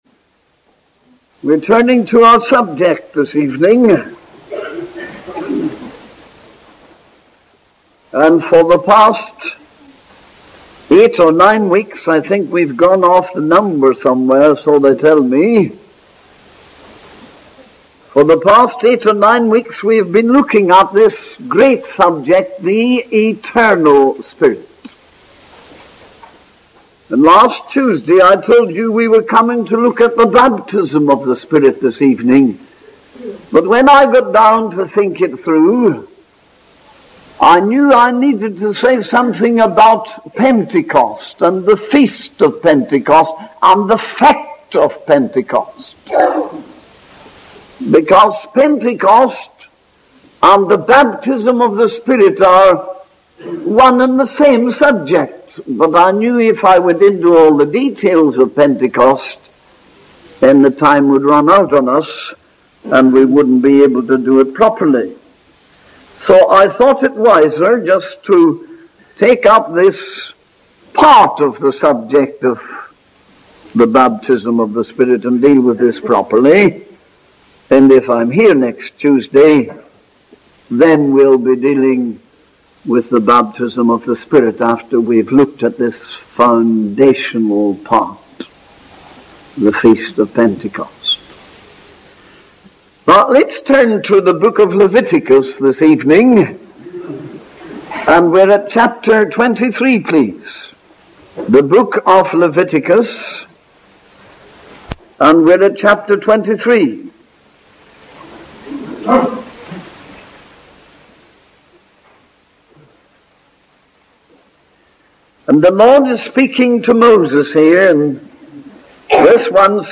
In this sermon, the preacher discusses the importance of preaching the word of God and the conviction it brings upon sinners.